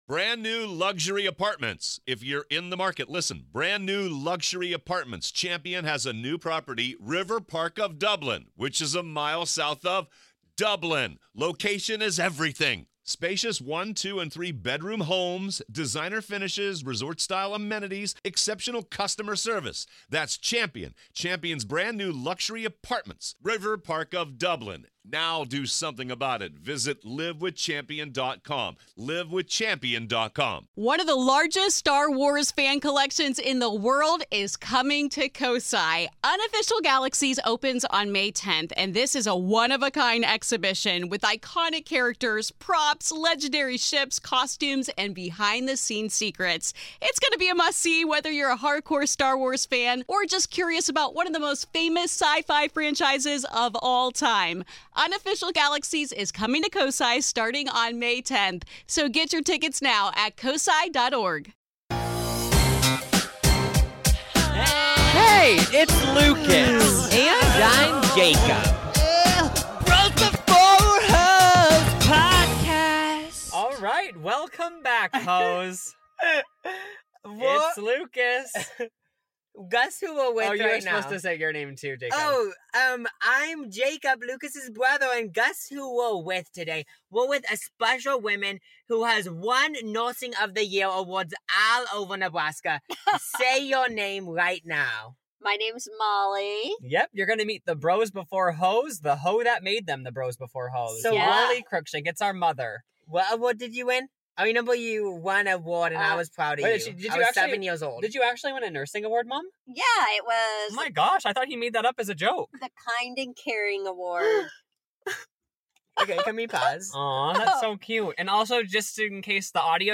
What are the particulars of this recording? We once hung out in her sweaty stomach as babies, and now we're all hanging out in a sweaty car for an hour for this podcast. We discuss everything from being forced to be married after becoming pregnant as a teen to Selena Gomez!